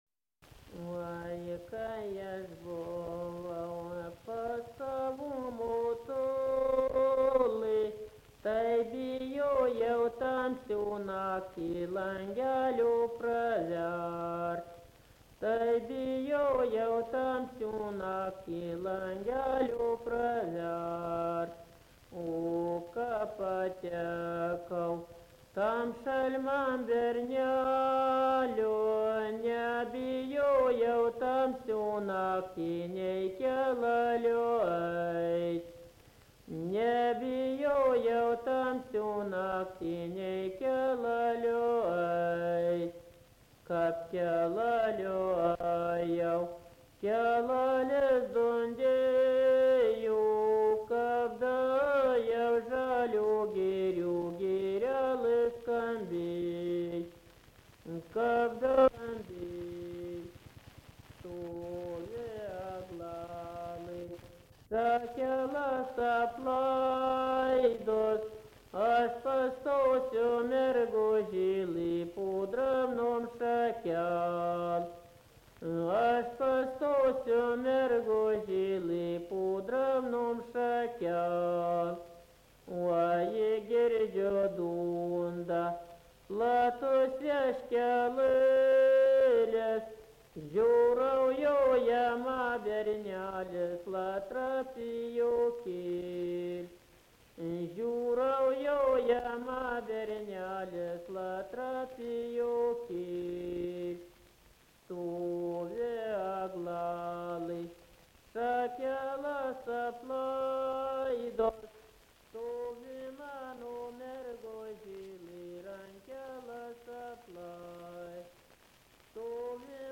Subject daina
Erdvinė aprėptis Čižiūnai (Varėna)
Atlikimo pubūdis vokalinis